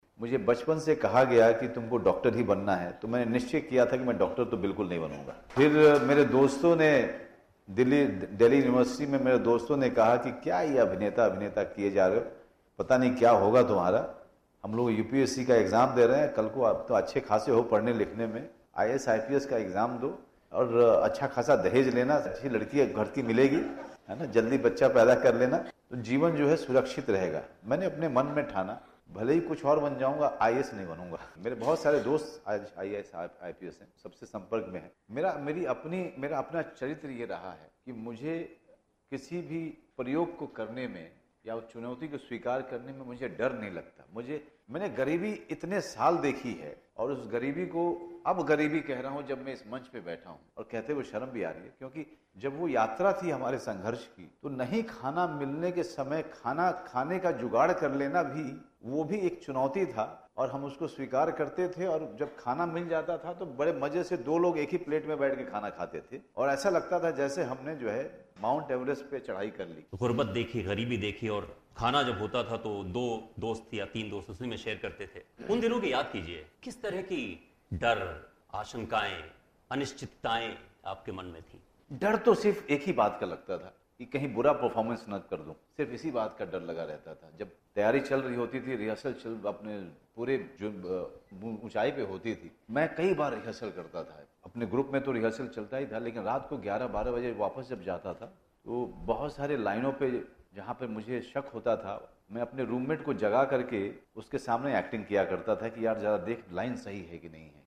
अपने शुरुआती दिनों के बारे में अभिनेता मनोज बाजपेई ने बात की